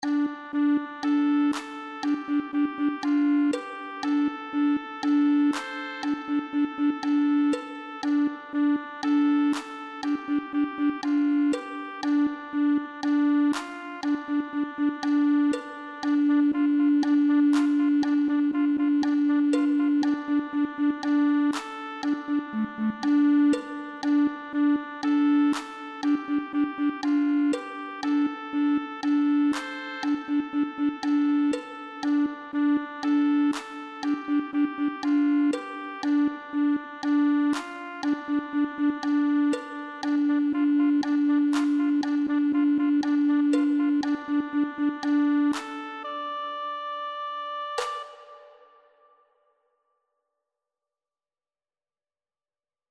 “Melodía con aire árabe para cantar- tocar y bailar„
2ª Voz